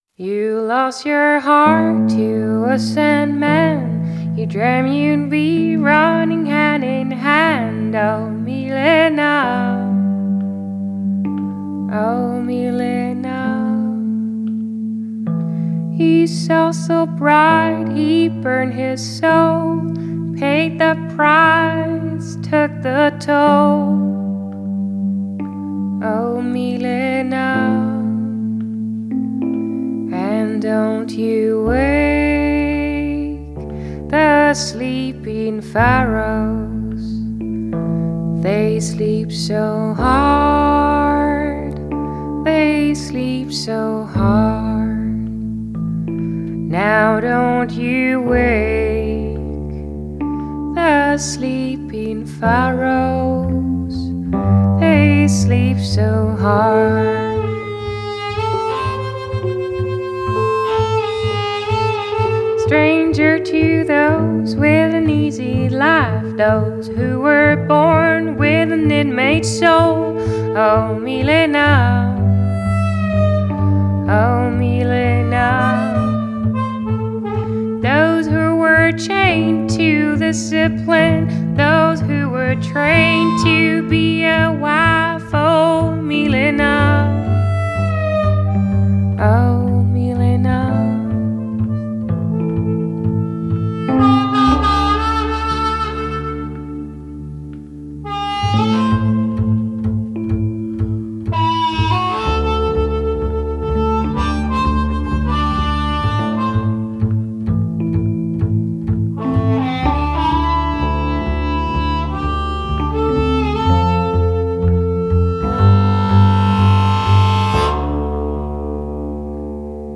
Genre: Folk, Indie, Country Folk, Female Vocal